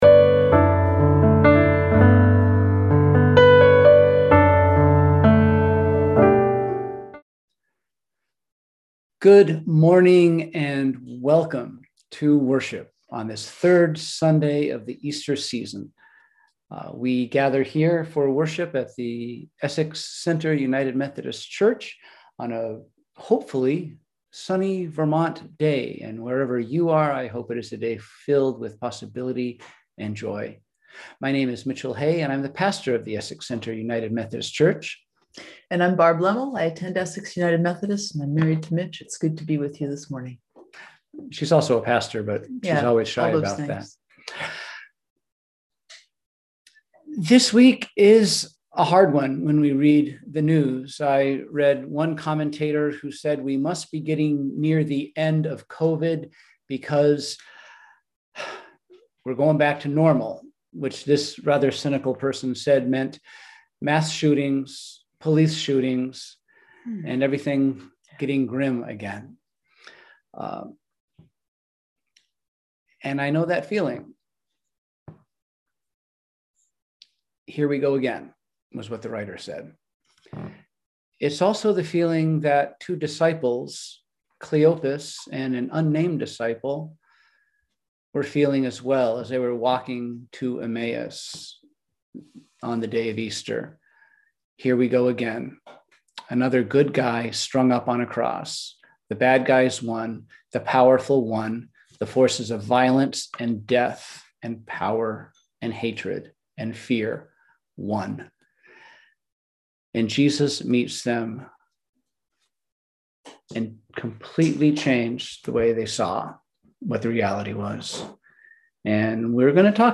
We held virtual worship on Sunday, April 18, 2021 at 10:00AM!